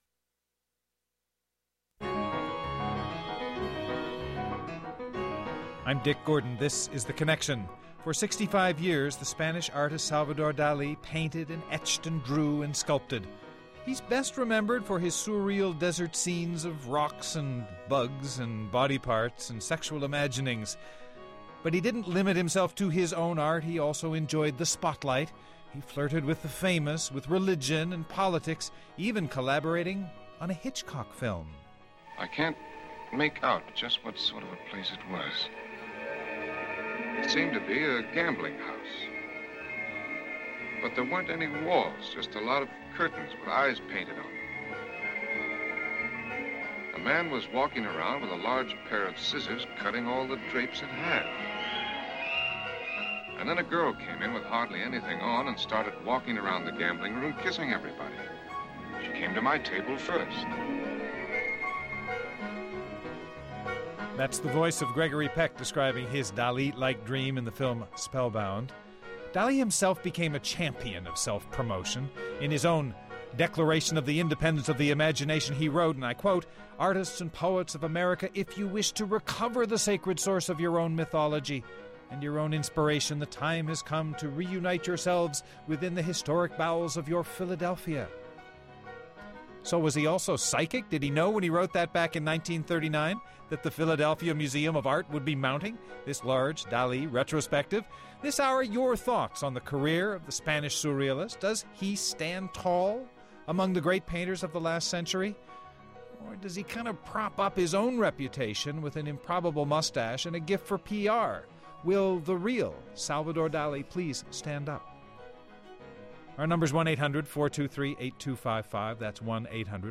Hear a conversation about the controversial life and art of Salvador Dali.